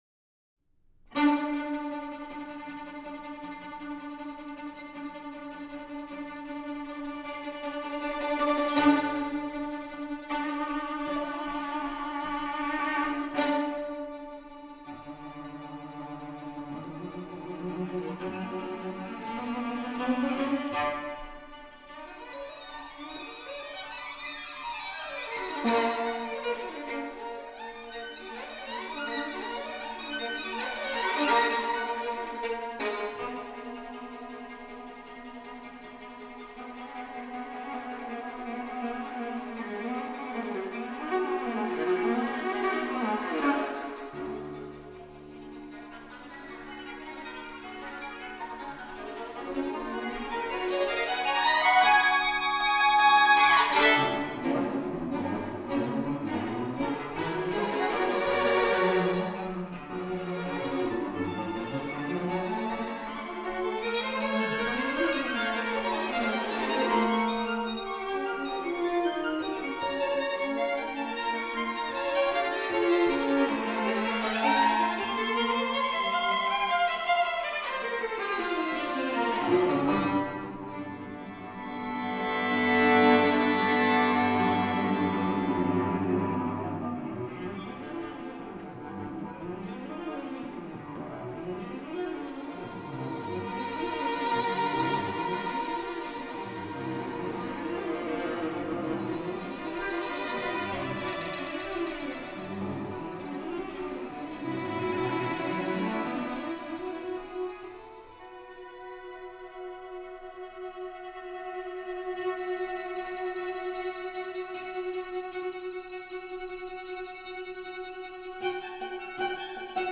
Recorded at the St. Norbert Arts Centre